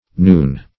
Noon \Noon\ (n[=oo]n), n. [AS. n[=o]n, orig., the ninth hour,